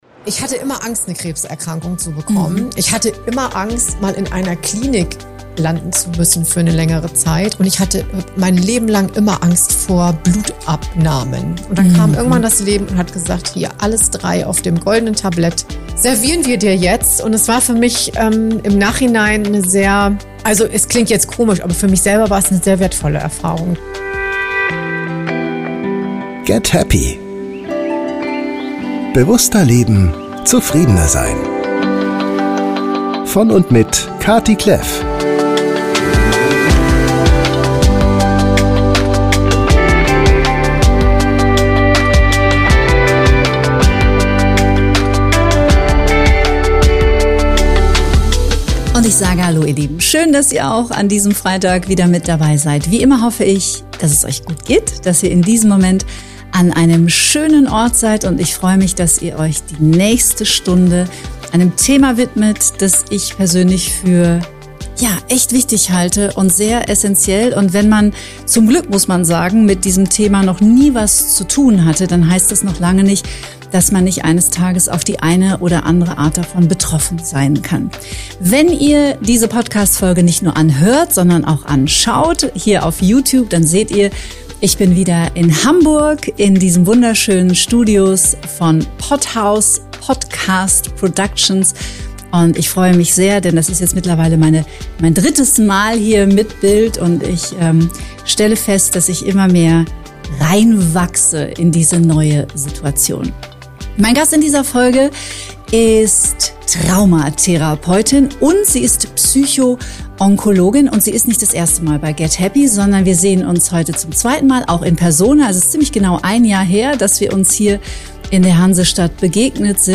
Beschreibung vor 4 Monaten In dieser Episode wird die Psycho-Onkologie als wichtige Unterstützung für krebserkrankte Menschen thematisiert. Die Gesprächspartner diskutieren die Notwendigkeit psychologischer Begleitung, persönliche Erfahrungen mit Krebs, die Rolle des Umfelds und der Angehörigen sowie die Auswirkungen toxischer Beziehungen auf die Gesundheit.